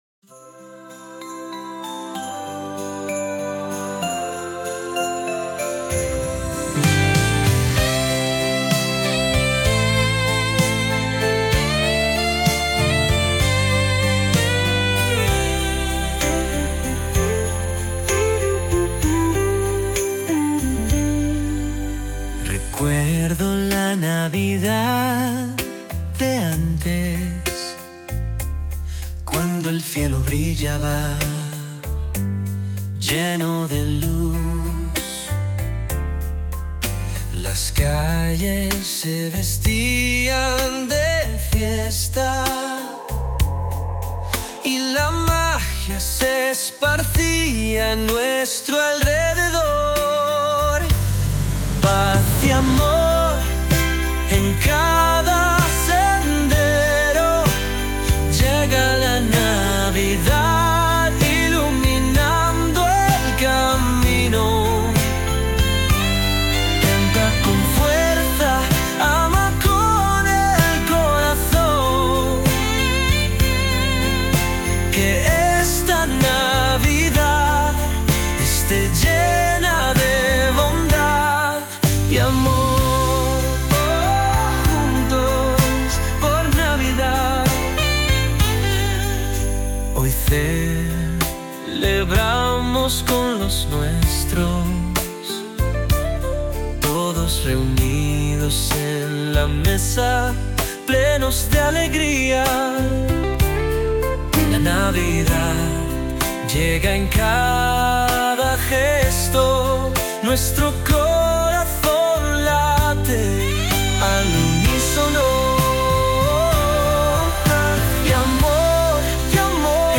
Con un tono cálido e inclusivo, se convierte en un himno perfecto para unir a las personas en torno al espíritu navideño.